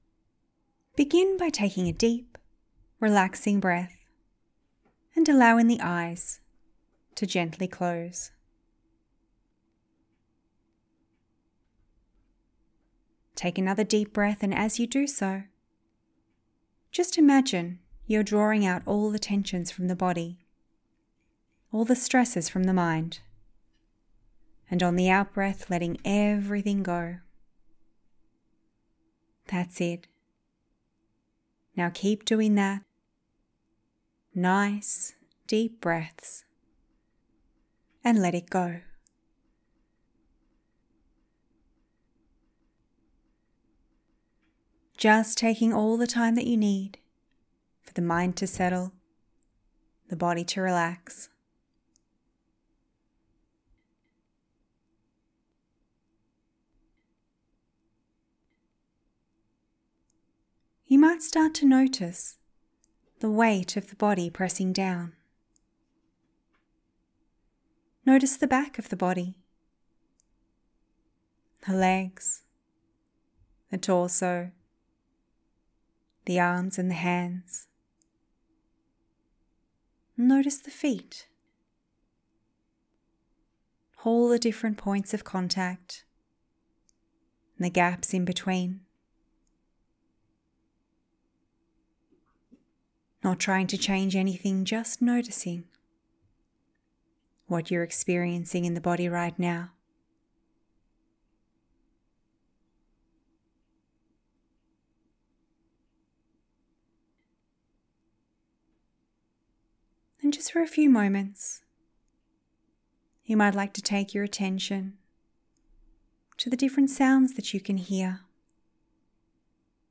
Download this 10-minute recording to be guided through a short mindfulness meditation